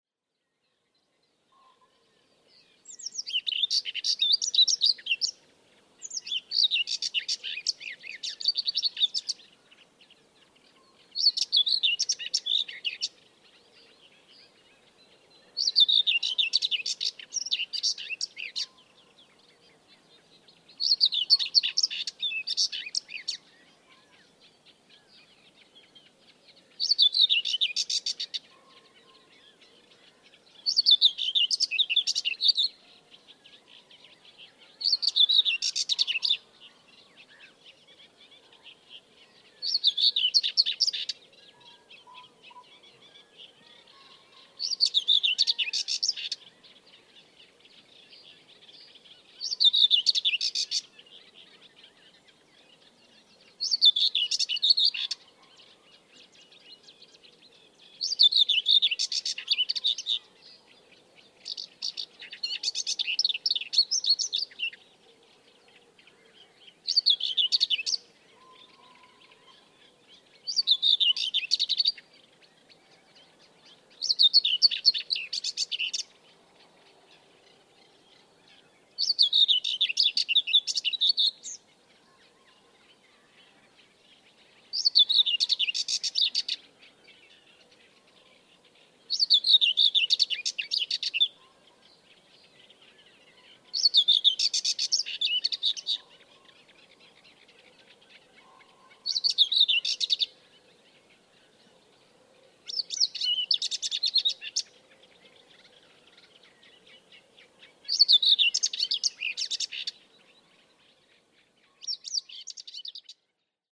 Whitethroat